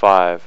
five.wav